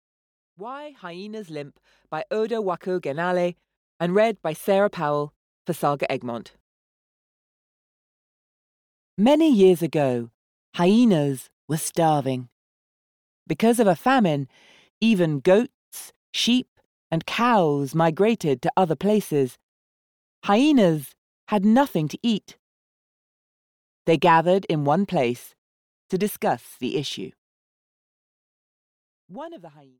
Why Hyenas Limp (EN) audiokniha
Ukázka z knihy